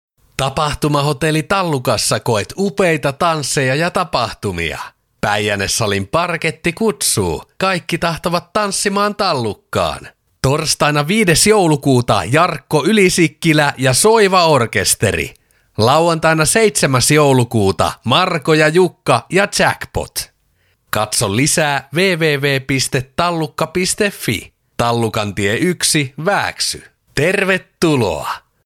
Kuuntele tästä viimeisin radiomainontamme Järviradiossa: